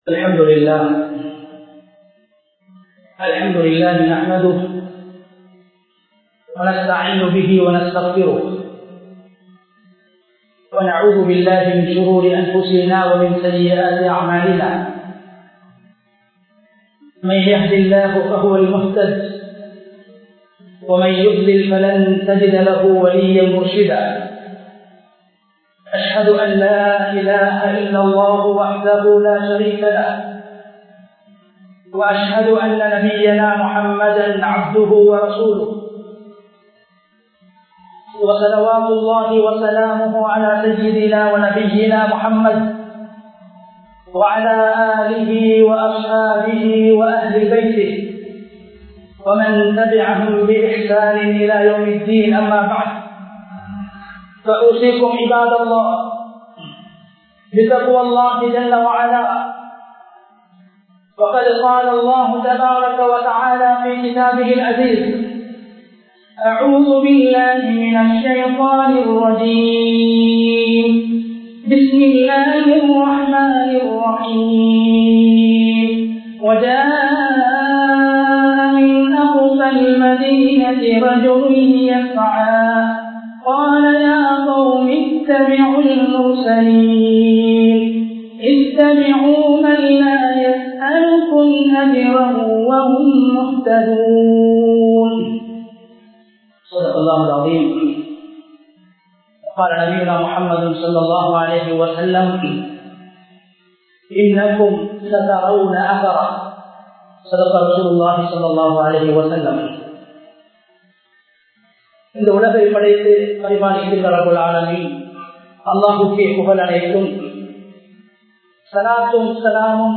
சூரா யாஸீன் கூறும் சமூகப்பற்று | Audio Bayans | All Ceylon Muslim Youth Community | Addalaichenai
Thihari Jumua Masjidh 2021-02-26 Tamil Download